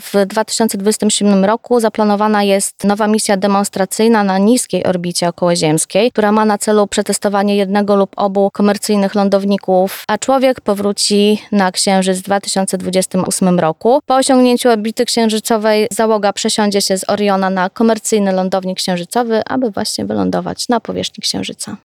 Całość rozmowy dostępna jest na platformach podcastowych: